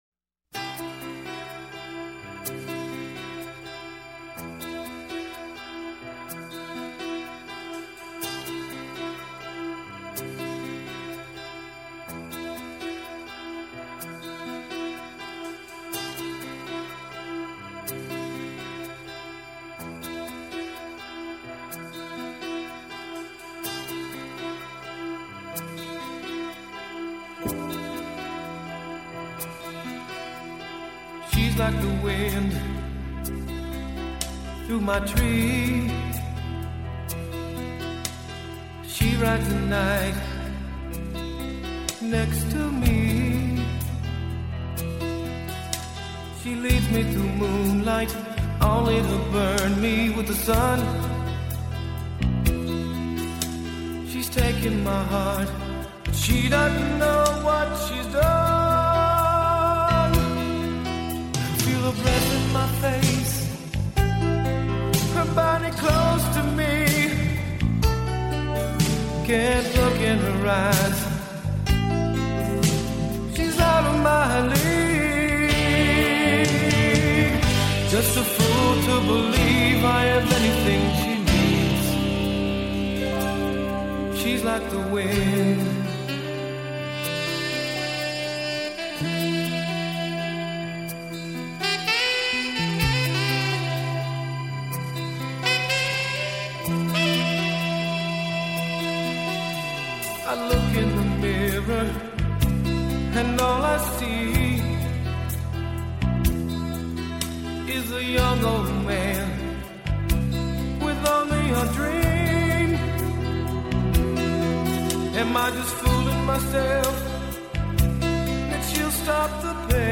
Жанр: Soul